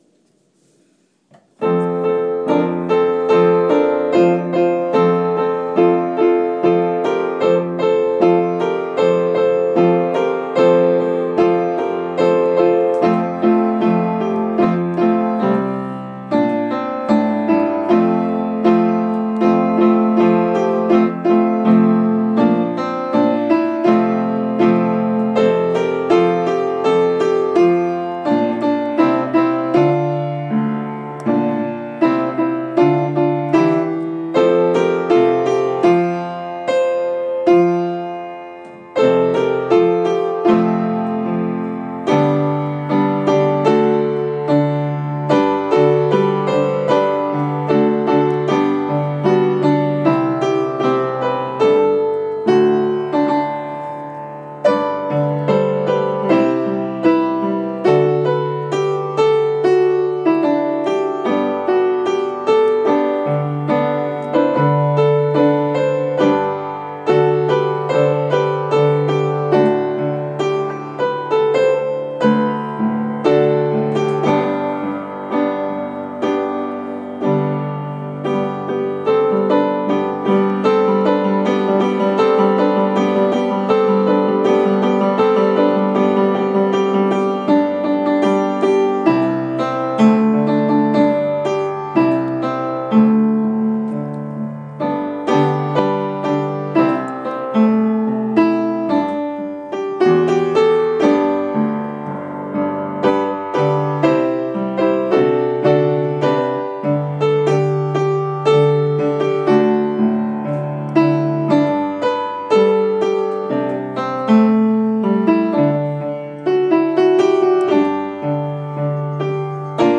Rehearsing Xmas carols